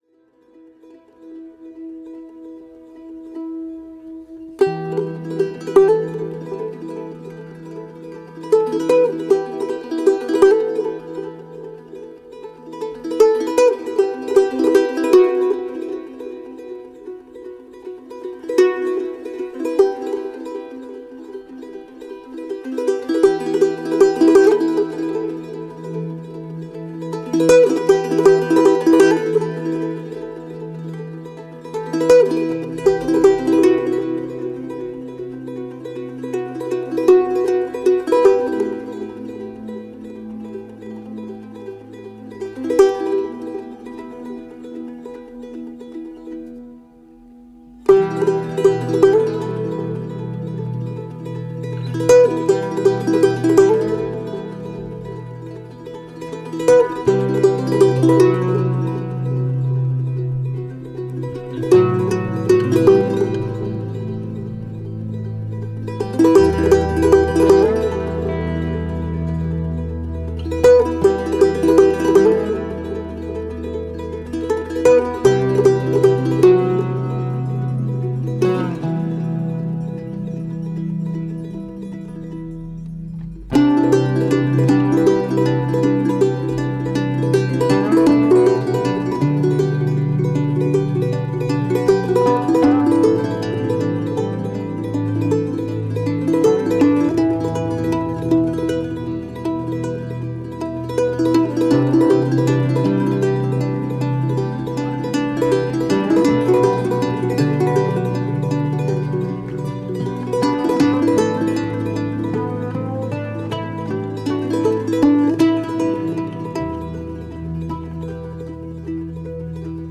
موسیقی بیکلام